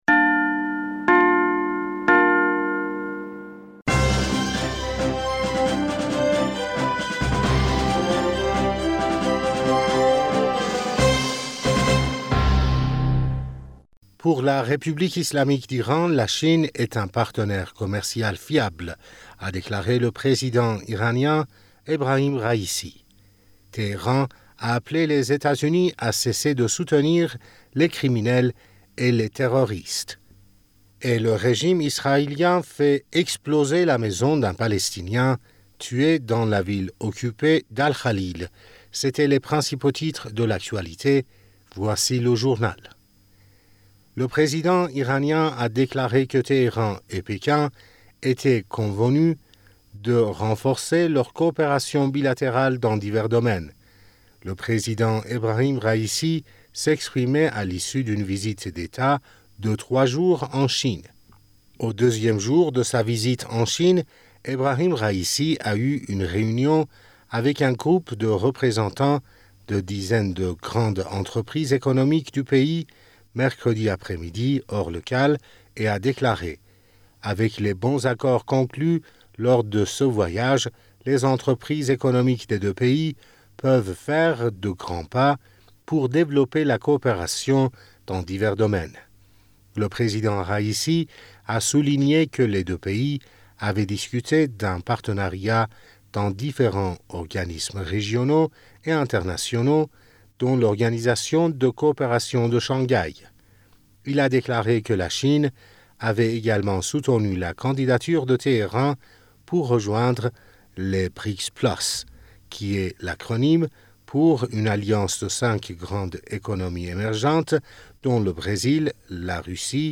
Bulletin d'information du 16 Février